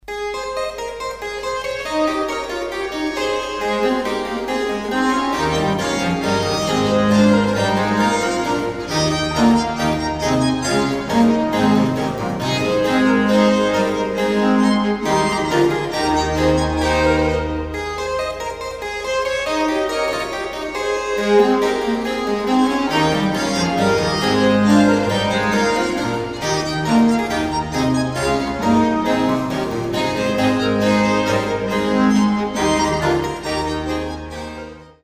Baroque chamber ensembles
suite for violin, 2 violas & continuo in A major